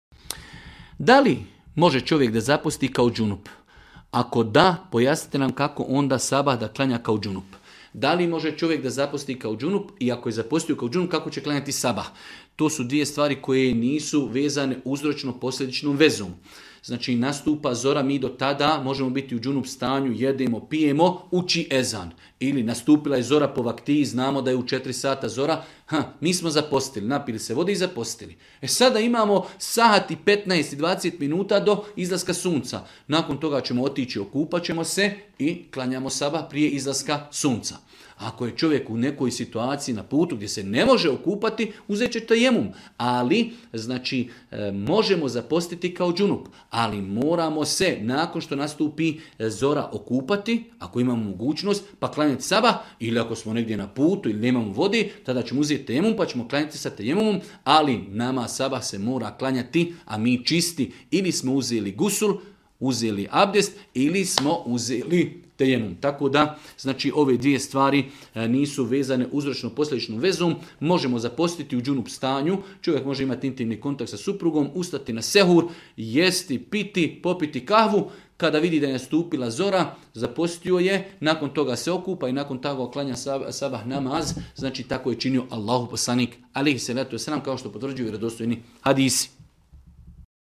u video predavanju ispod.